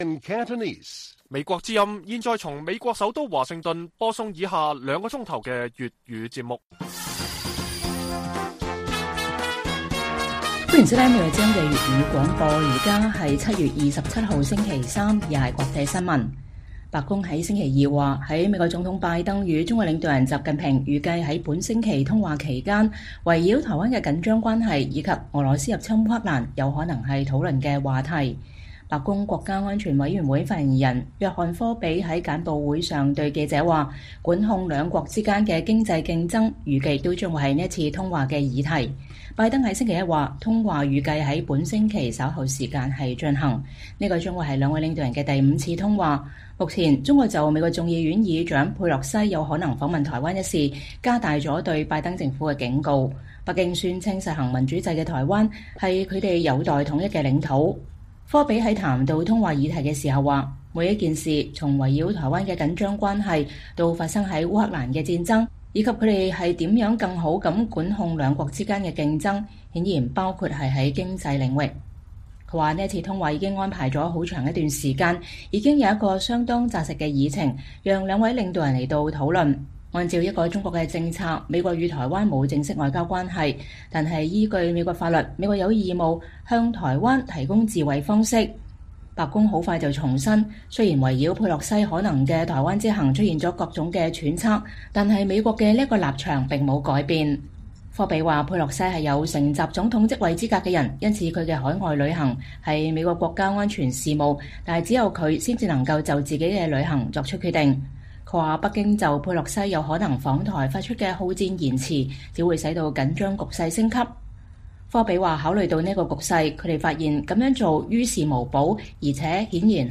粵語新聞 晚上9-10點：白宮官員說拜登與習近平通話議題將包括台灣與烏克蘭